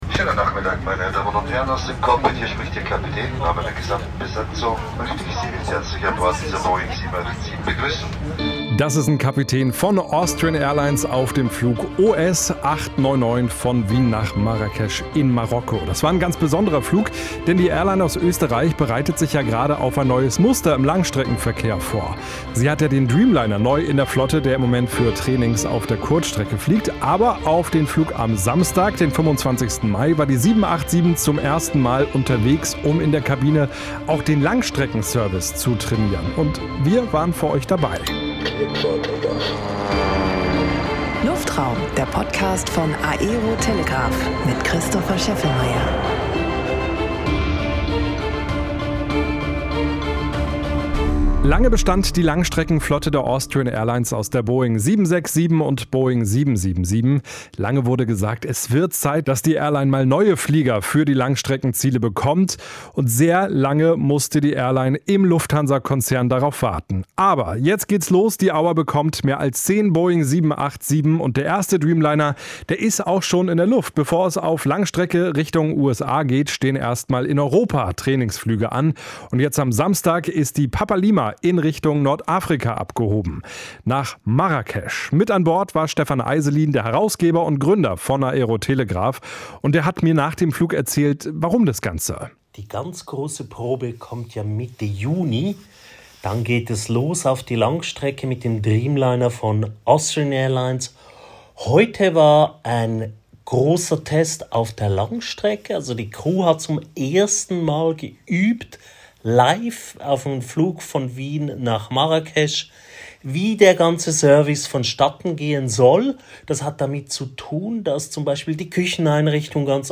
Jetzt am Samstag ist eine Boeing 787 in Richtung Nordafrika abgehoben. Auf dem Weg nach Marrakesch wurde zum ersten Mal der echte Langstreckenservice trainiert. Wir waren für euch an Bord.